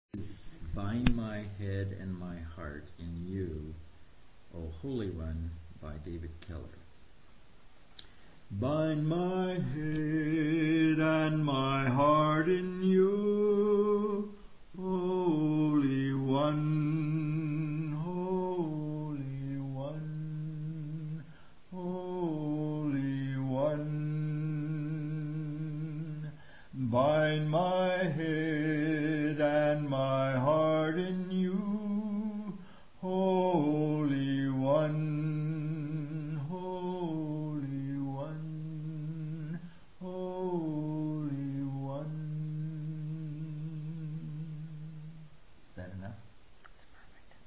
Chant